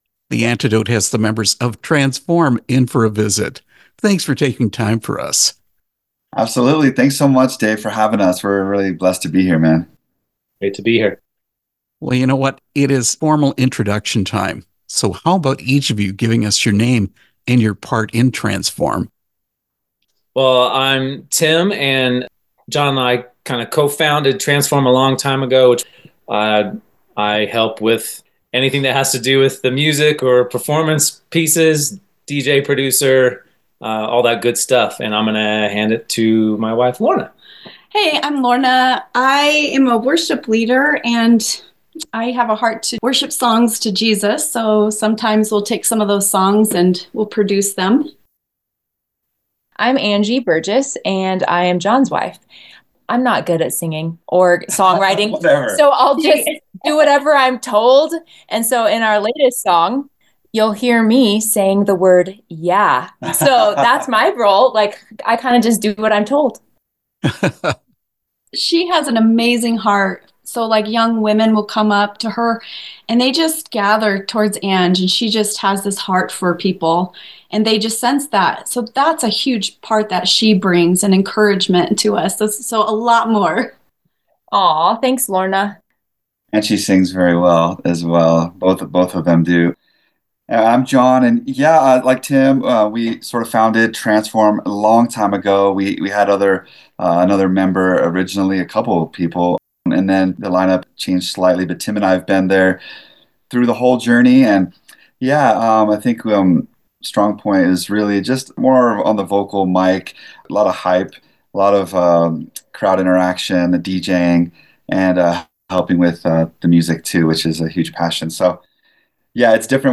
Interview with Transform
transform-interview.mp3